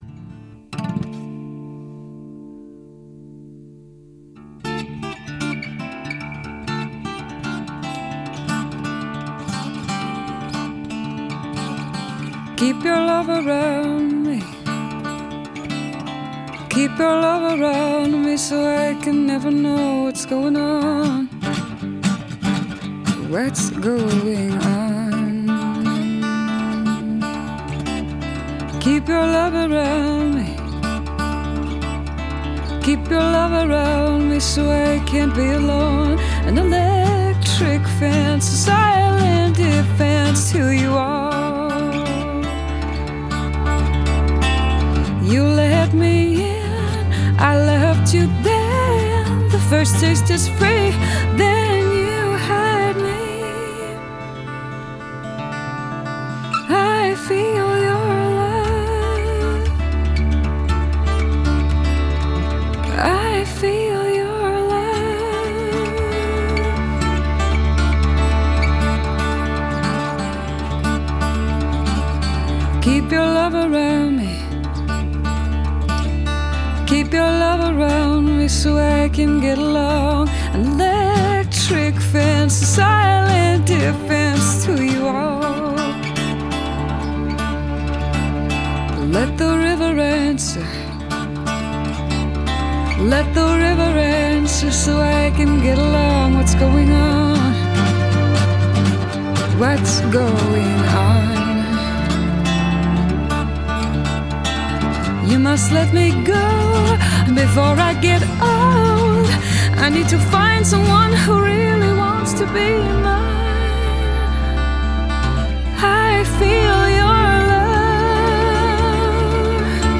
Genre: Indie Folk, Alternative